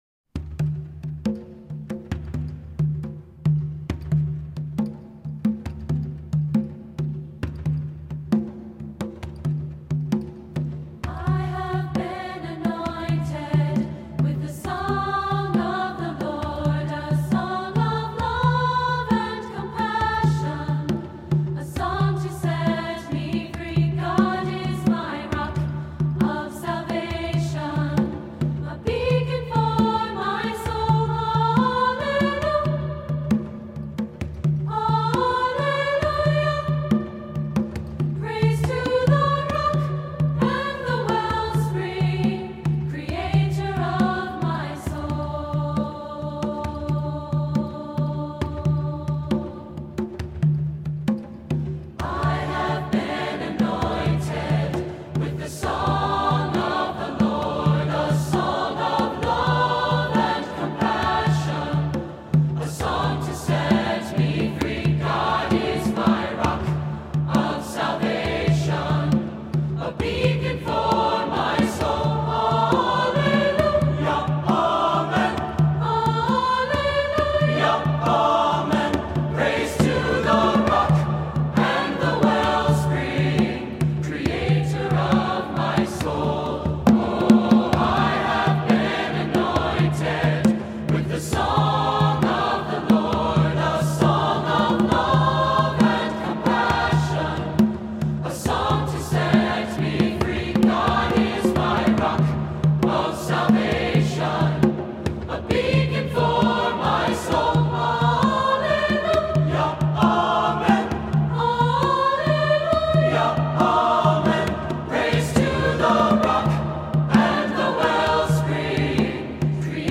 Voicing: SATB/Drum